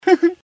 Laugh 31